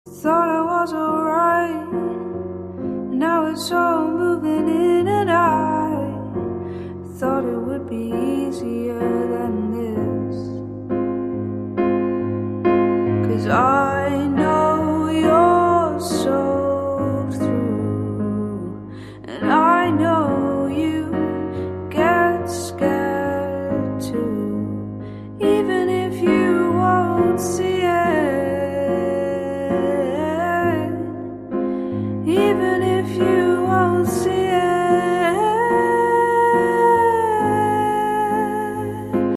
M4R铃声, MP3铃声, 欧美歌曲 26 首发日期：2018-05-14 05:21 星期一